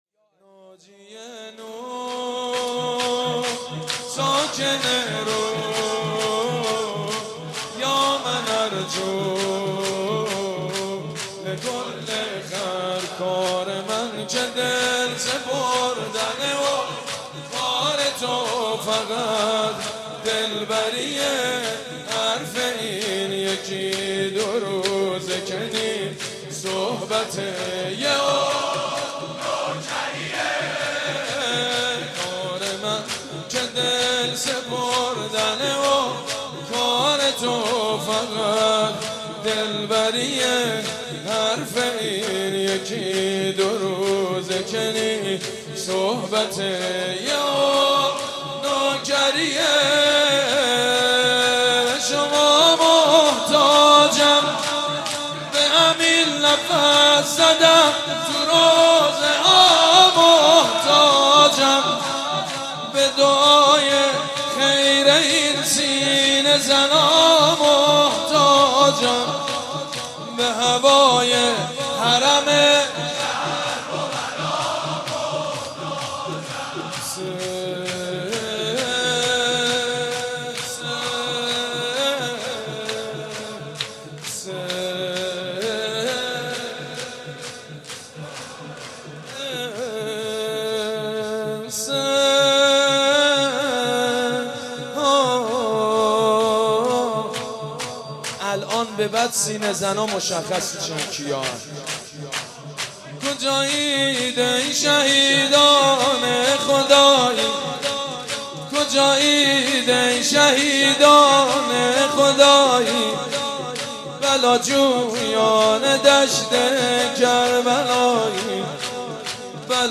«شهادت امام جواد 1393» شور: کار من که دل سپردن و کار تو فقط دلبری